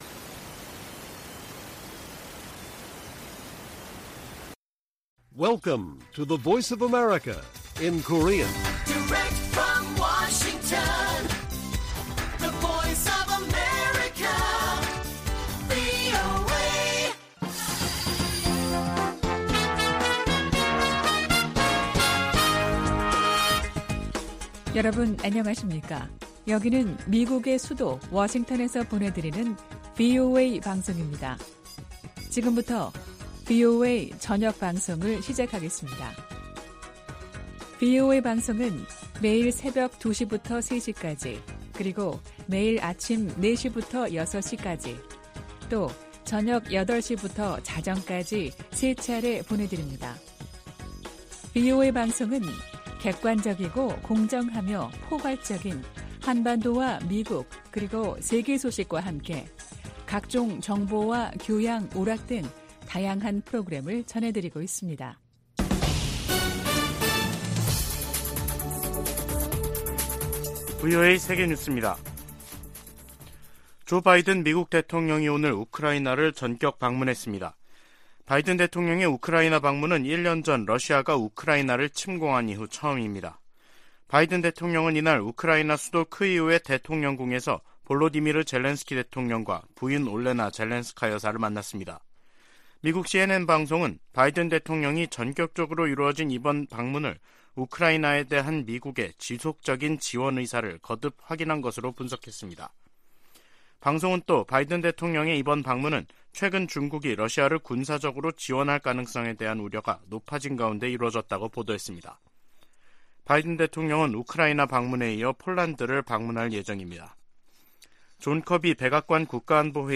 VOA 한국어 간판 뉴스 프로그램 '뉴스 투데이', 2023년 2월 20일 1부 방송입니다. 북한이 ‘화성-15형’ 대륙간탄도미사일(ICBM)을 쏜 지 이틀 만에 평안남도 숙천 일대에서 동해상으로 초대형 방사포를 발사했습니다. 미국과 한국, 일본 외교장관이 긴급 회동을 갖고 북한의 ICBM 발사를 규탄하면서 국제사회의 효과적인 대북제재 시행을 촉구했습니다. 한국 정부는 북한의 ICBM 발사 등에 대해 추가 독자 제재를 단행했습니다.